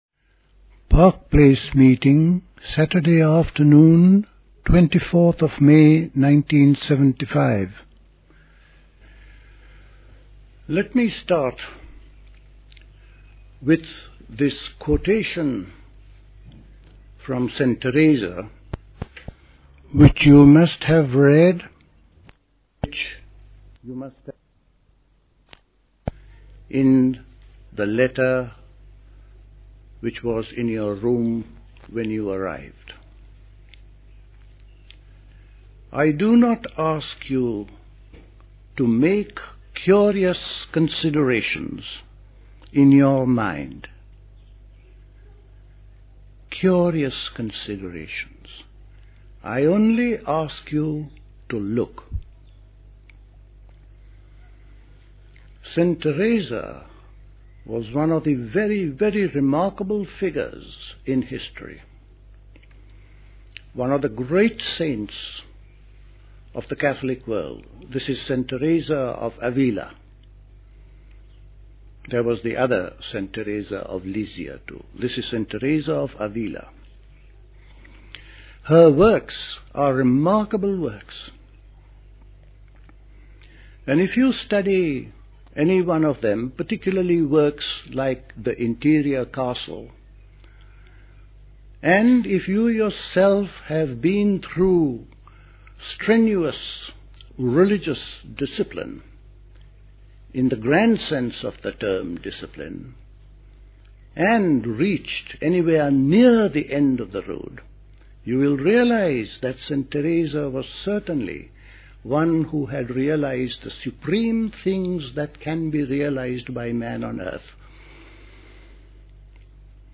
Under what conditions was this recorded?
Recorded at the 1975 Park Place Summer School.